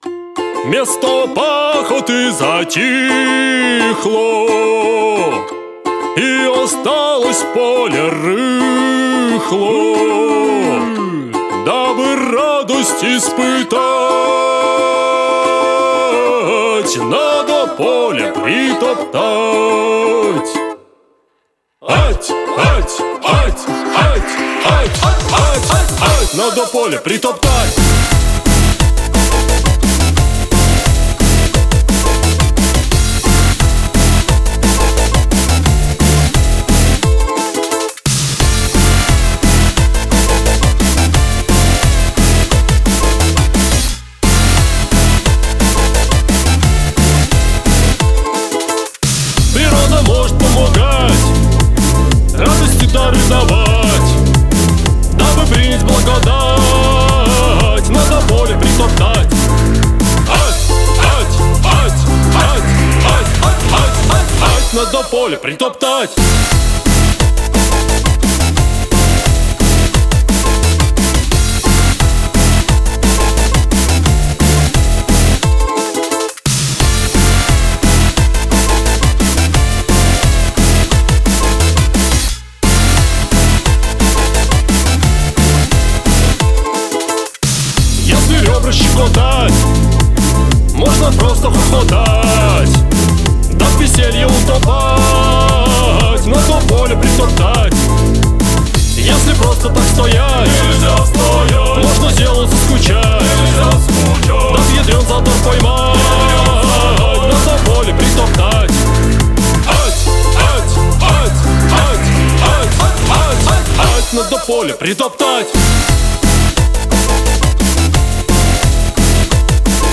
• Качество: 192, Stereo
позитивные
Bass
DnB
балалайка
русские народные
Веселая песня :)